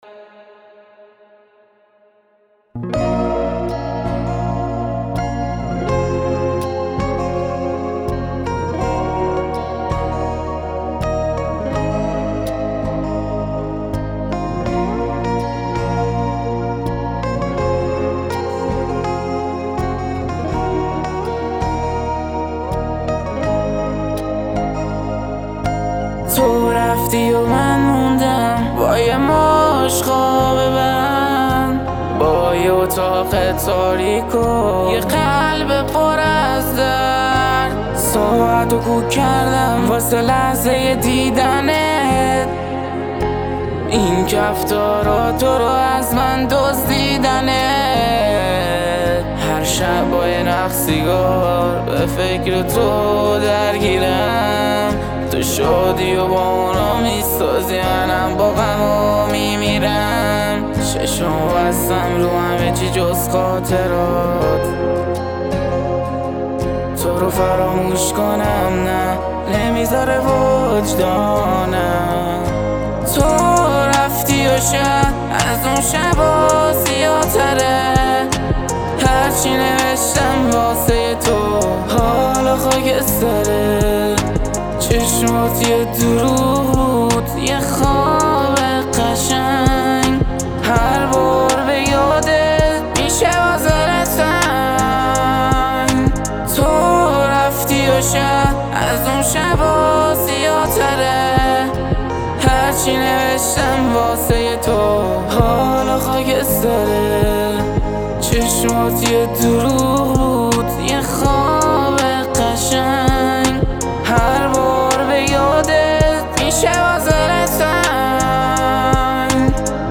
رپ فارسی RnB
ولی اینکه همش اتوتیون